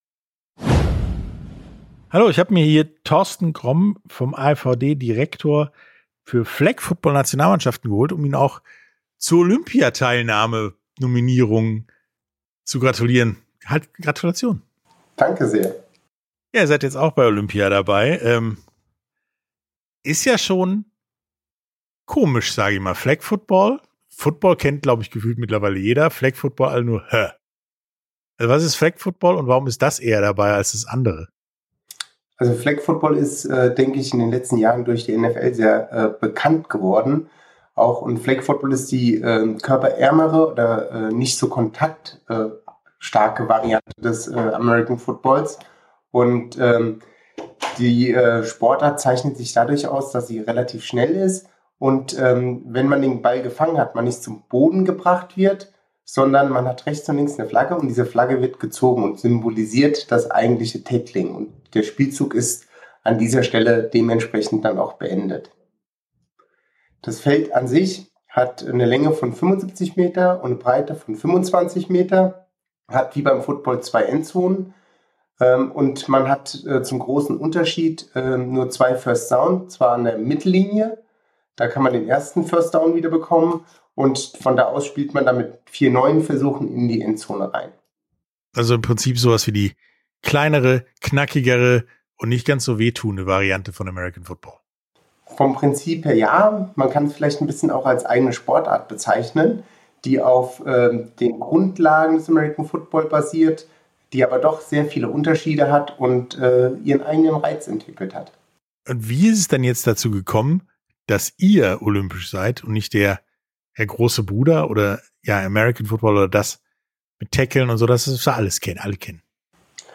hier das Interview Flag Football Olympia